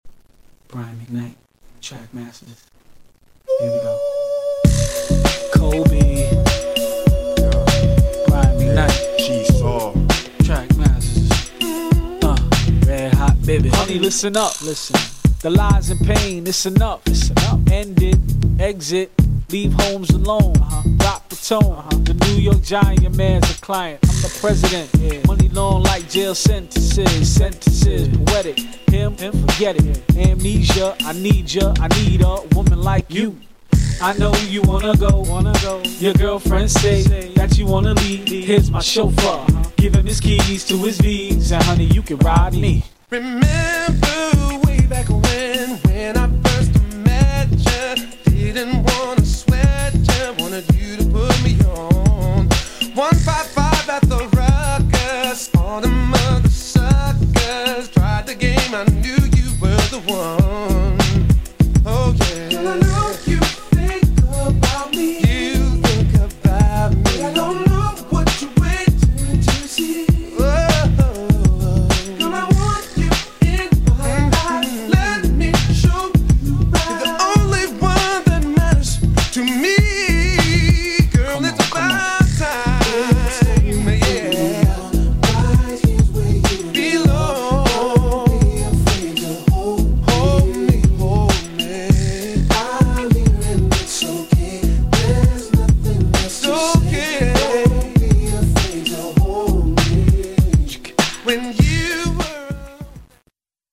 GENRE R&B
BPM 96〜100BPM